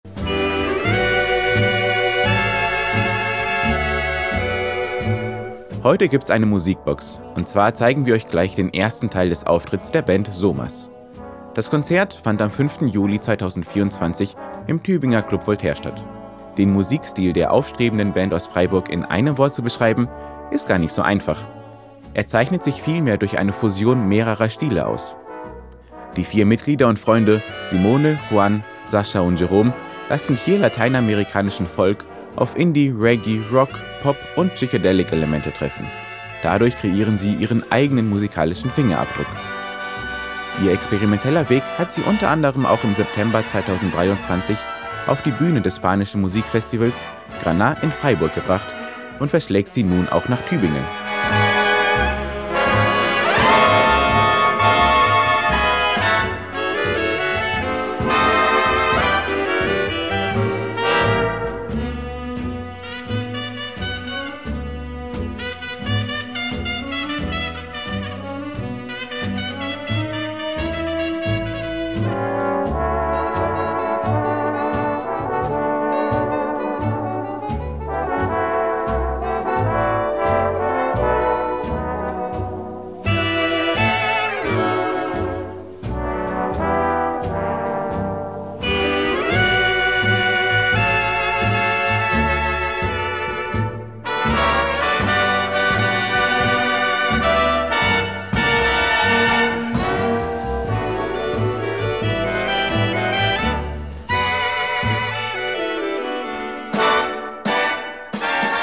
Er zeichnet sich eher durch eine Fusion mehrere Stile aus.
Drums
Gitarre, Gesang und Keybord
E-Gitarre und Gesang
Bass.